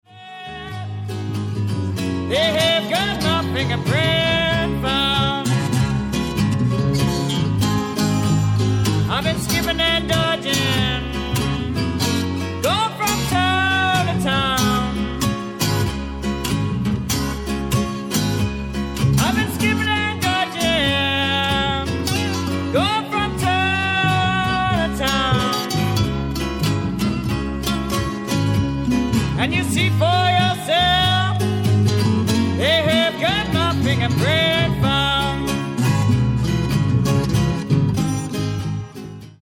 BLUES ROCK / COUNTRY BLUES